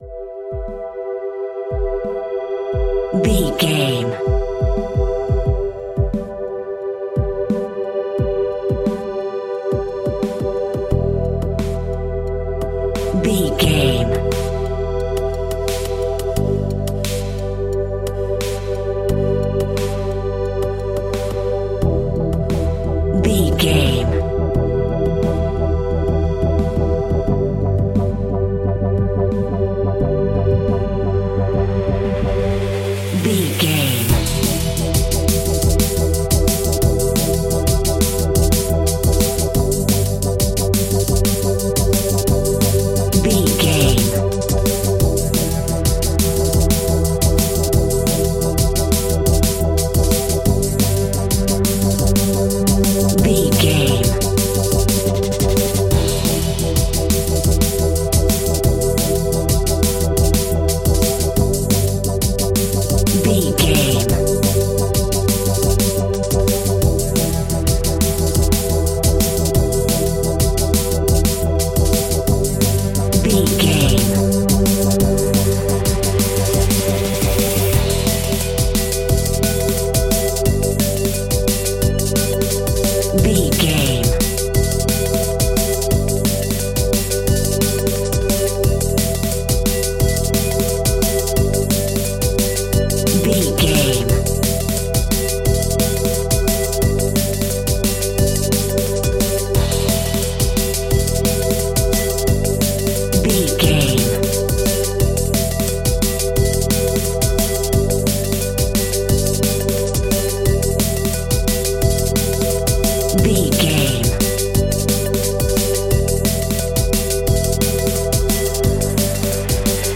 Aeolian/Minor
Fast
aggressive
powerful
futuristic
hypnotic
industrial
dreamy
drum machine
synthesiser
electronic
sub bass
synth leads
synth bass